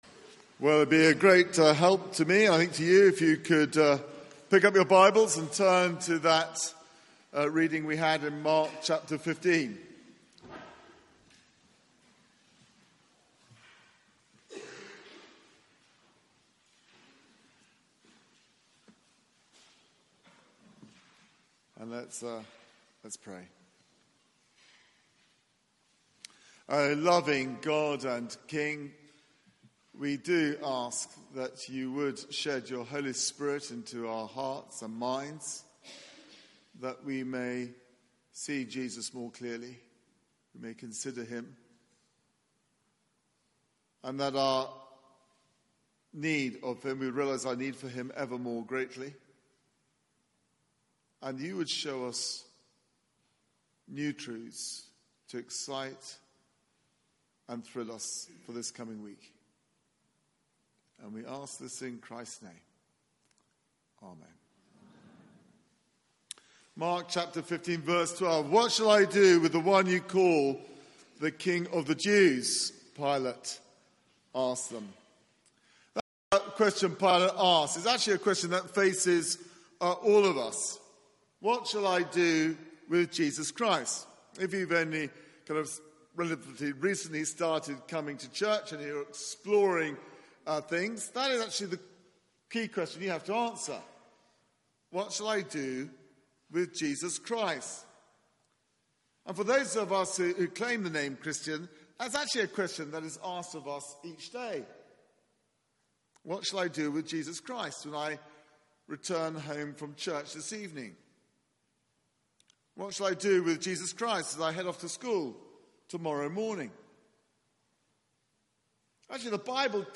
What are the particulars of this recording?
Media for 6:30pm Service on Sun 11th Mar 2018 18:30 Speaker